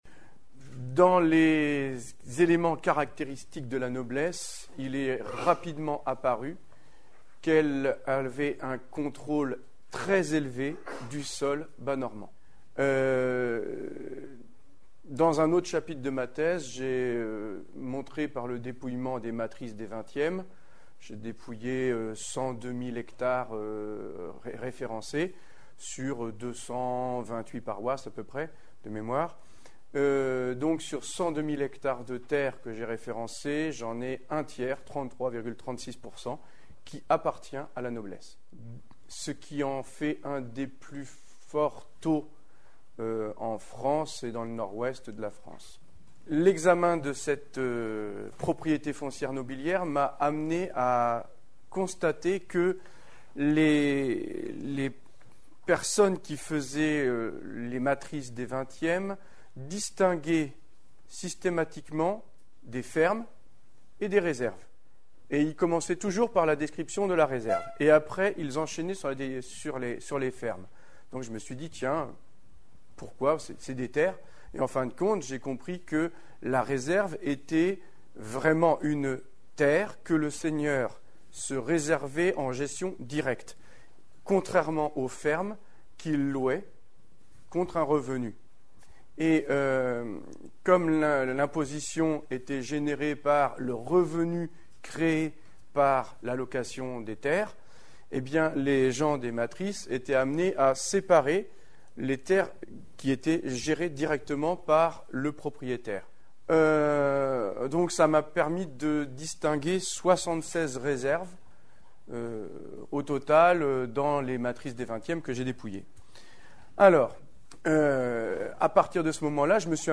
L'intervention